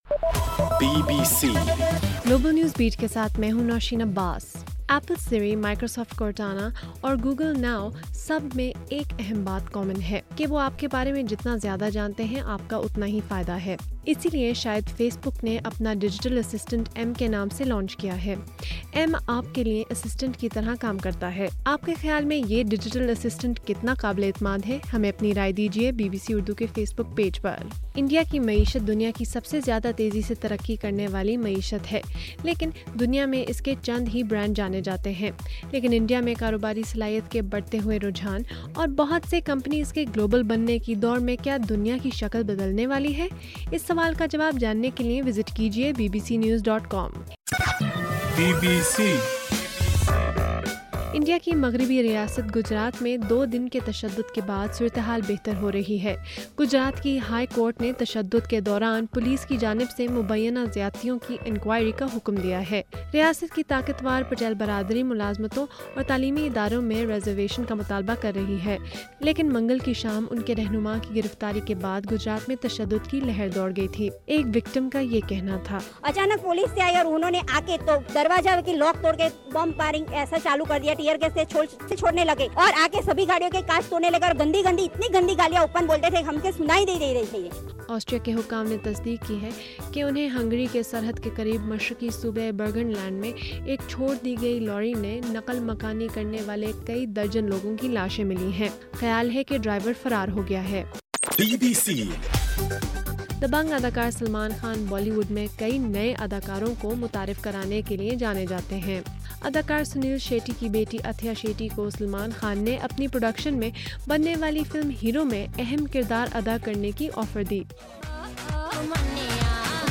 اگست 27: رات 8 بجے کا گلوبل نیوز بیٹ بُلیٹن